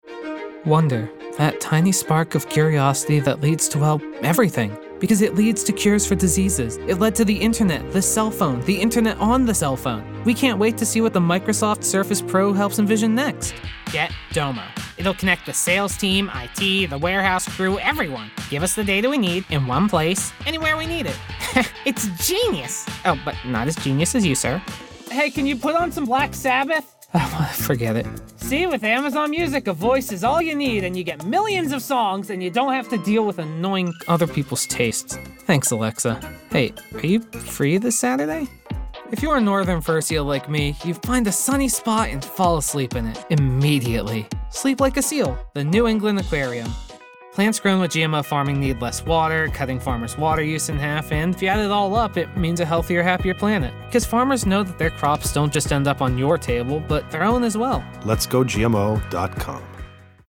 Teenager, Young Adult, Adult
Location: Cincinnati, OH, USA Voice Filters: VOICEOVER GENRE COMMERCIAL 💸 GAMING 🎮